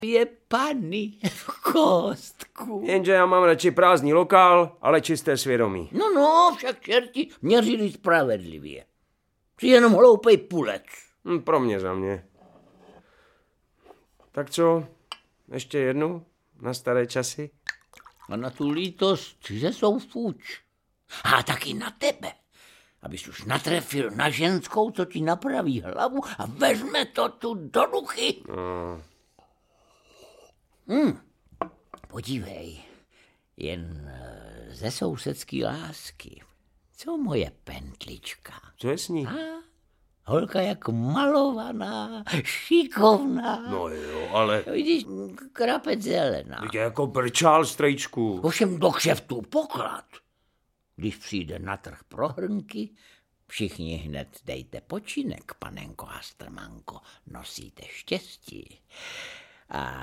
Audiobook
Read: Jiří Lábus